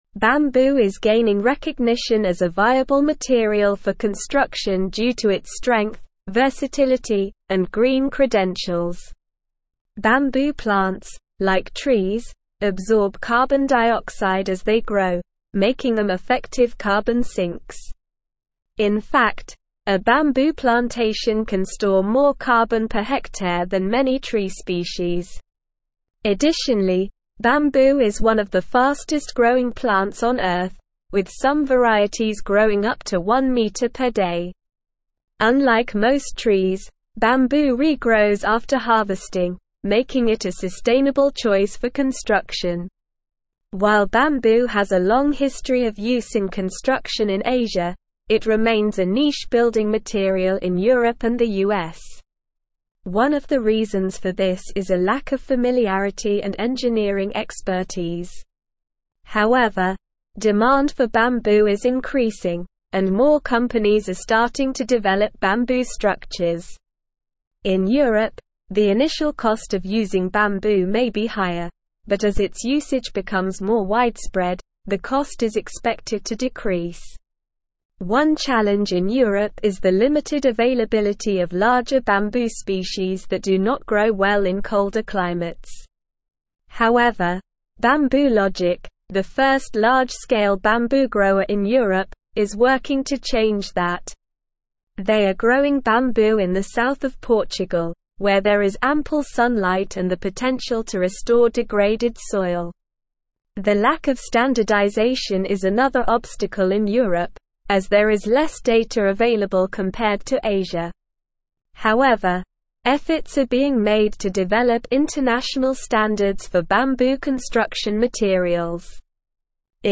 Slow
English-Newsroom-Advanced-SLOW-Reading-Bamboo-A-Sustainable-and-Versatile-Building-Material.mp3